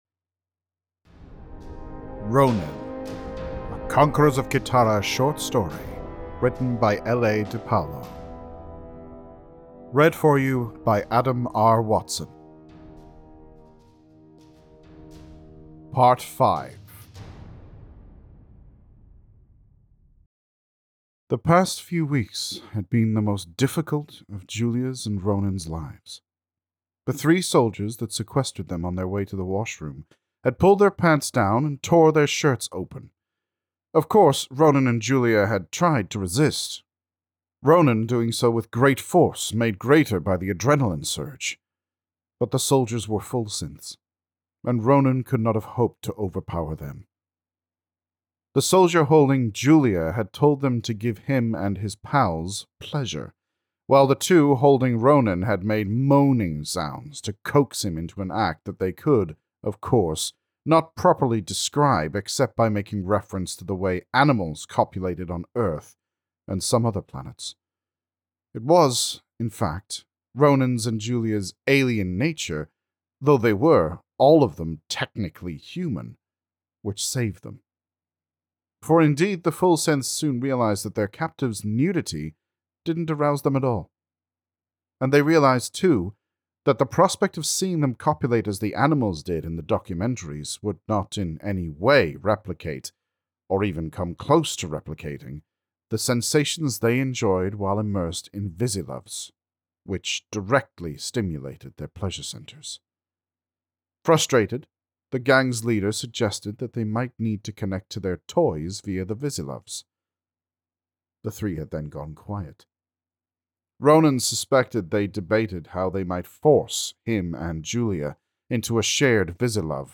Publication year: 2024 Purchase the ebook here Download the free PDF Or download the free audiobook